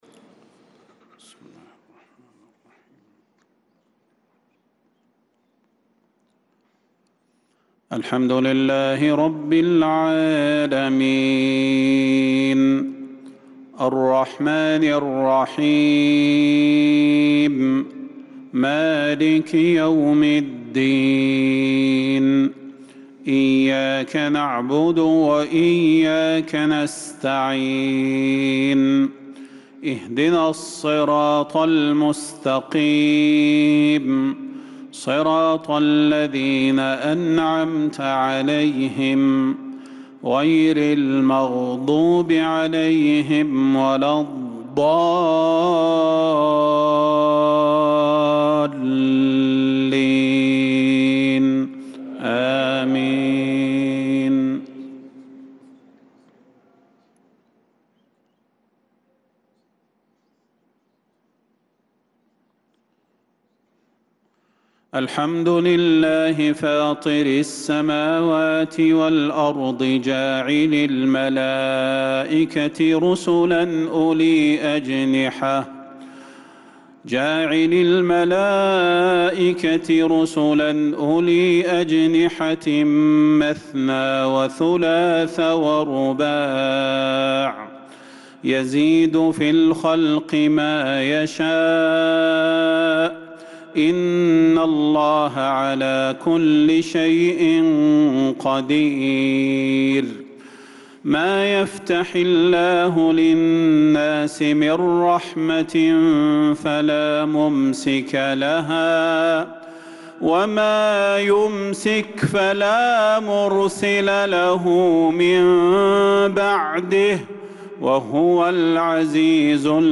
صلاة العشاء للقارئ صلاح البدير 21 رجب 1446 هـ
تِلَاوَات الْحَرَمَيْن .